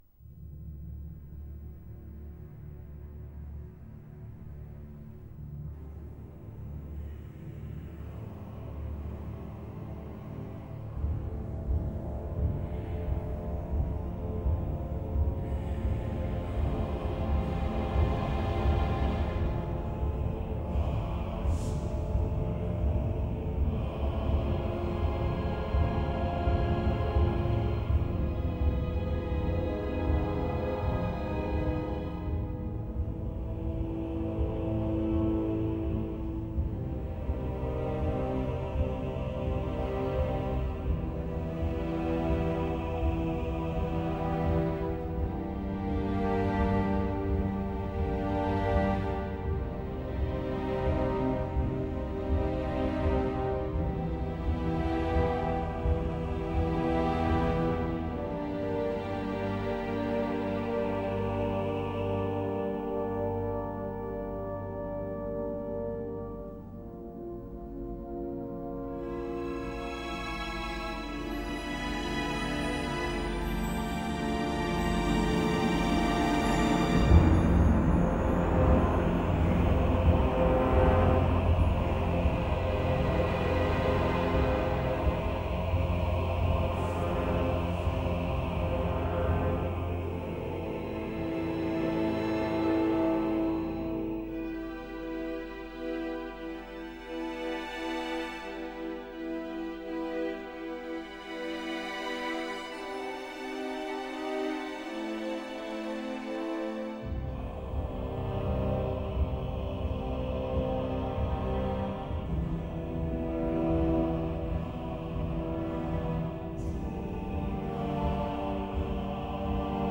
I think we need some cave music.